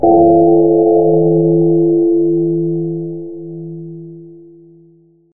ゴーーーーーーーン」と鐘が鳴る音。